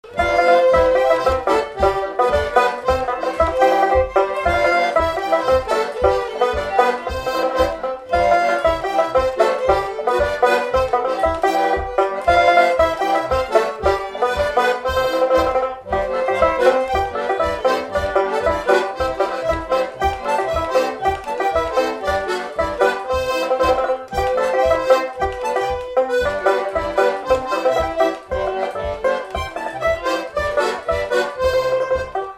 Séga
Instrumental
danse : séga
Pièce musicale inédite